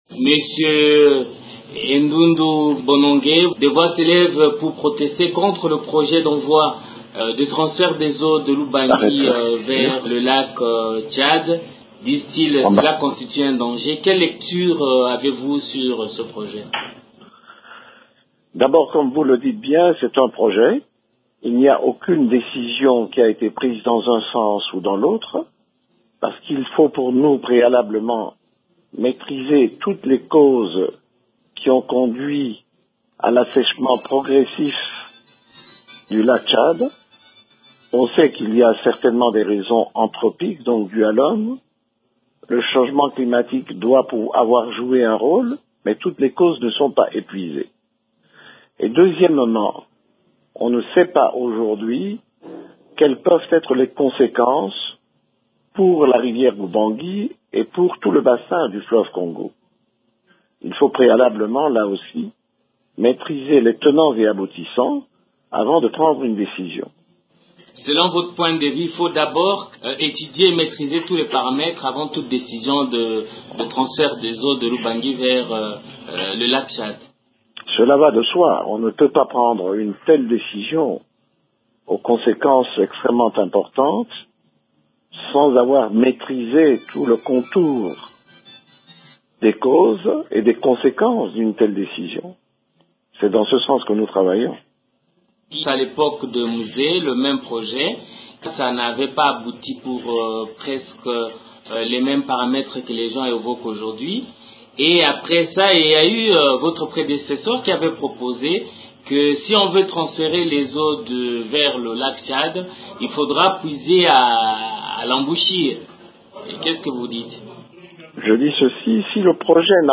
José Endundo, Ministre de l’Environnement, conservation de la nature et tourisme